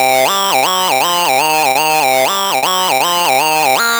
Abradacabra C 120.wav